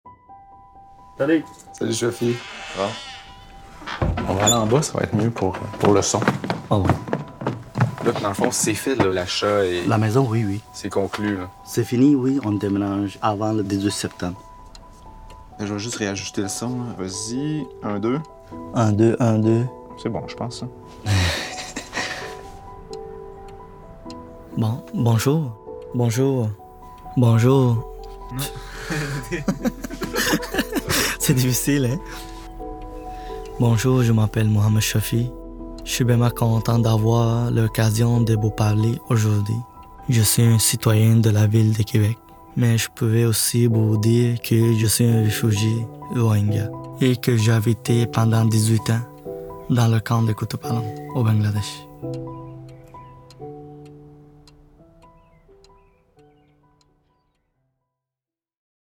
les bruits du camp et ceux de son quotidien actuel — composent un parcours sonore immersif inédit